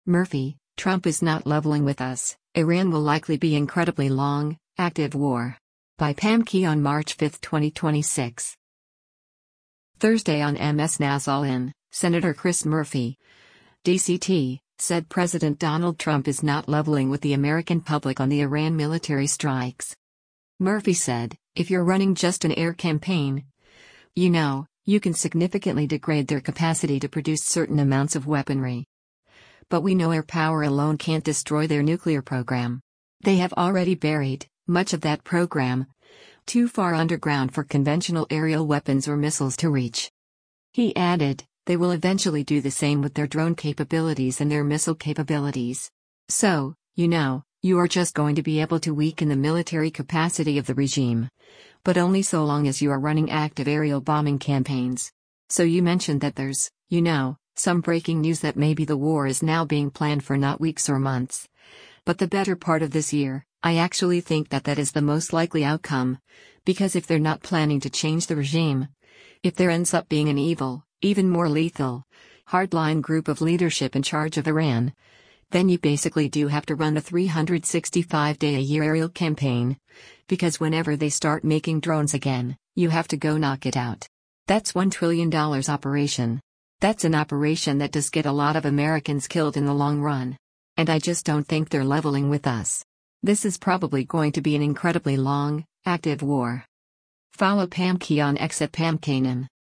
Thursday on MS NOW’s “All In,” Sen. Chris Murphy (D-CT) said President Donald Trump is not “leveling” with the American public on the Iran military strikes.